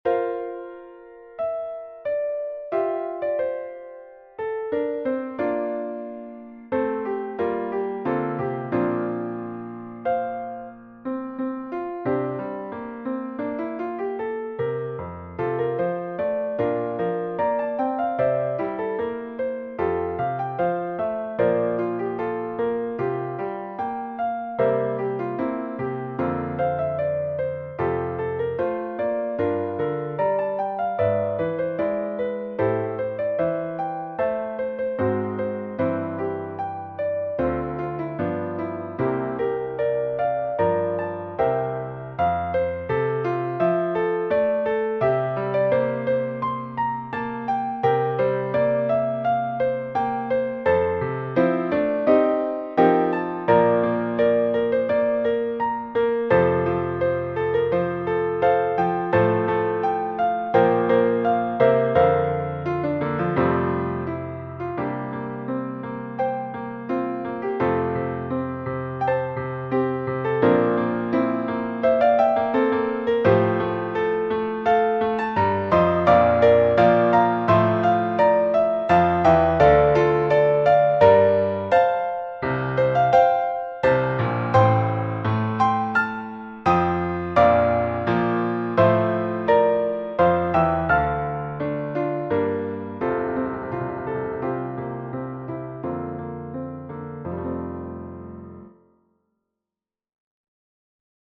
Patriotic